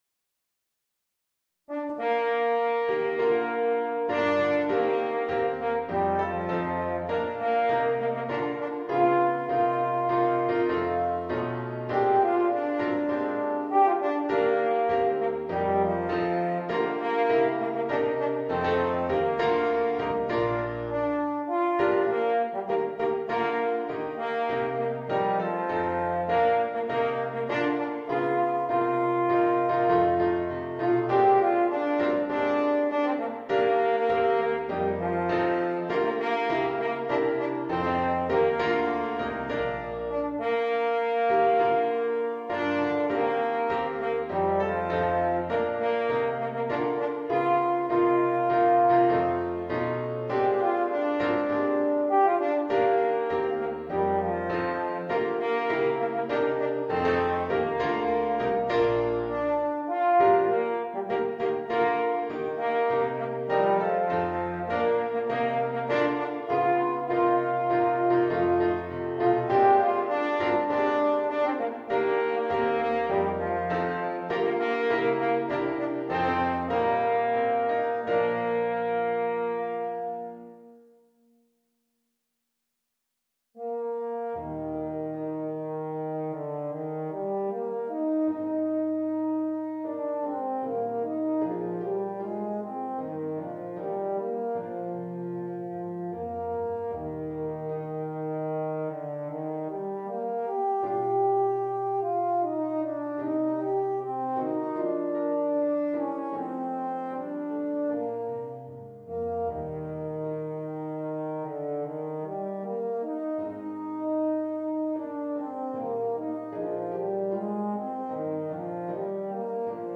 Cor en Mib